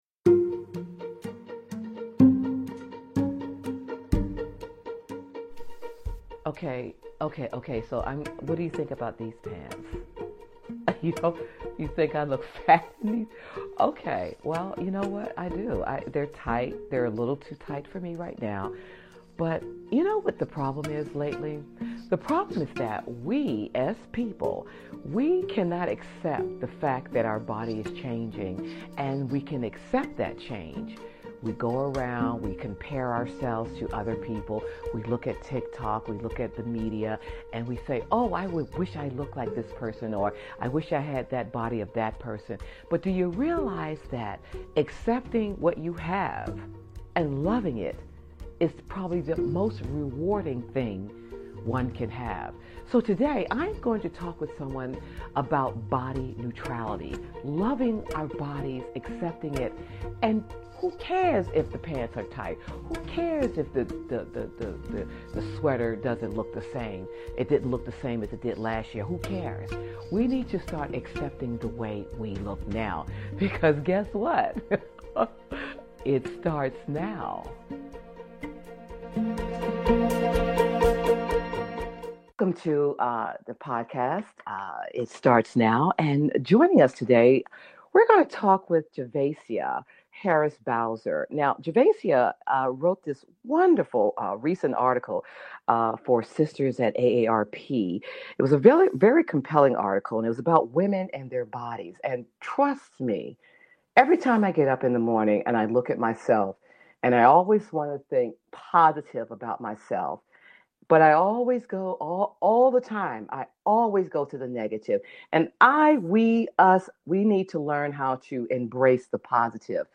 It Starts Now Talk Show
Podcast Bio : is a lively, conversational podcast that flips the script on aging.
This show is a fresh, funny, and feel good-space where getting older is celebrated-not dreaded.